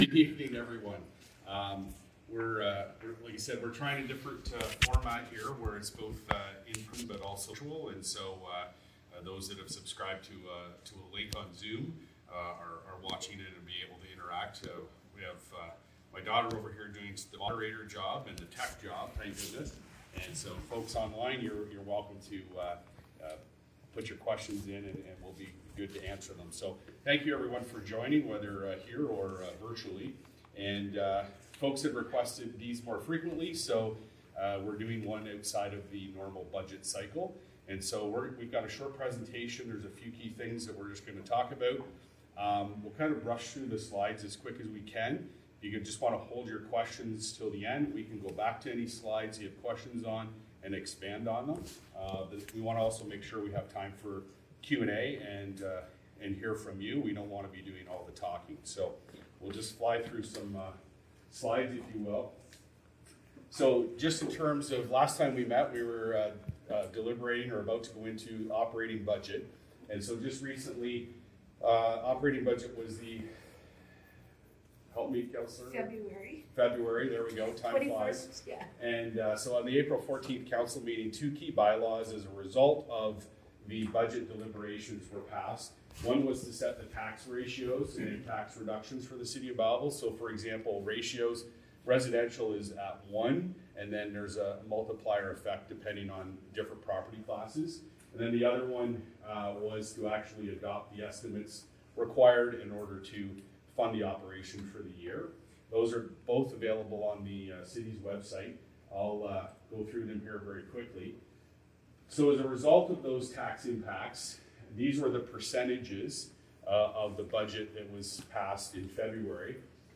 Belleville city councillors for Thurlow ward held a town hall at the Gerry Masterson Community Centre on Tuesday.
Kathryn Brown and Paul Carr spoke to both residents in attendance as well as those online through Zoom.